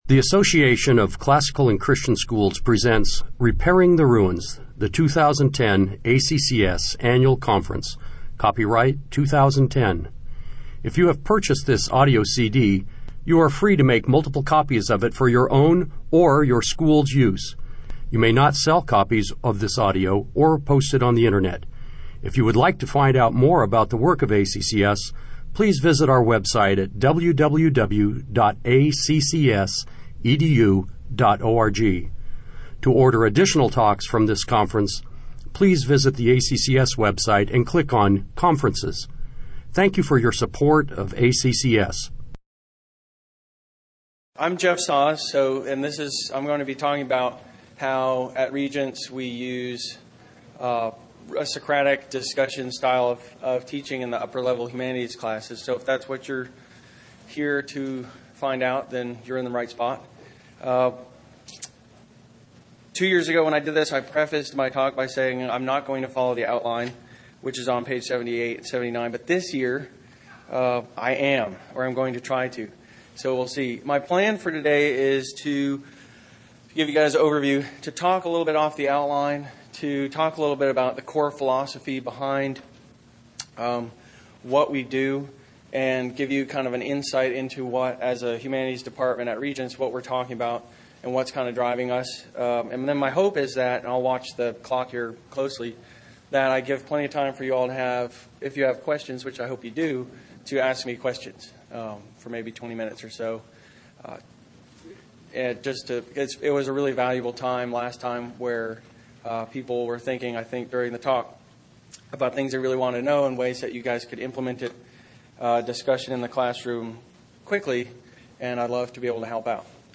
2010 Workshop Talk | 1:13:53 | 7-12, General Classroom, History, Literature
The Association of Classical & Christian Schools presents Repairing the Ruins, the ACCS annual conference, copyright ACCS.